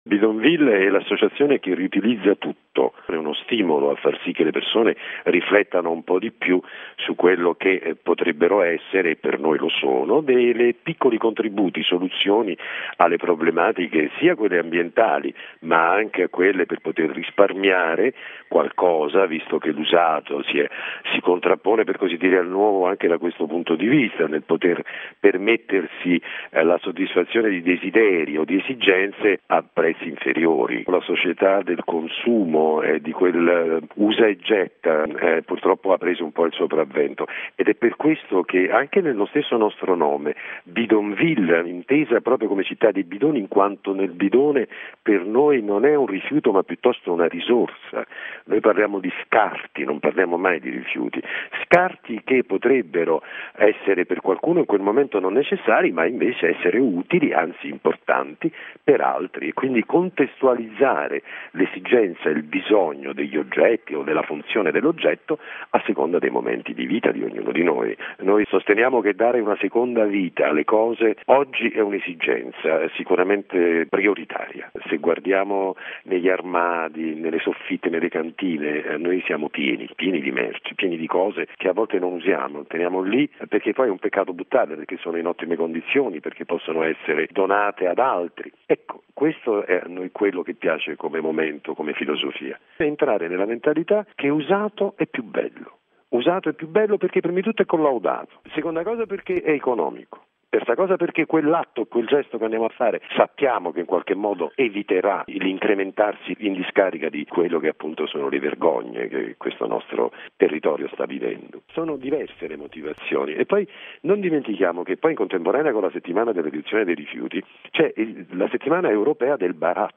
Al microfono di